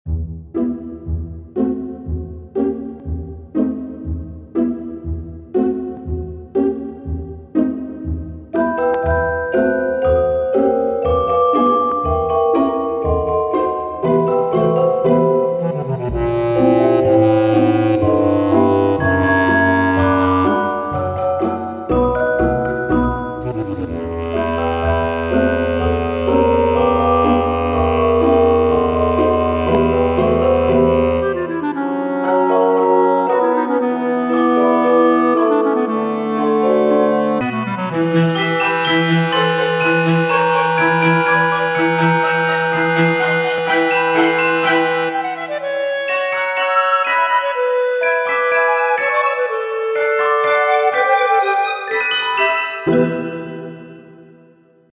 Imagen de una celesta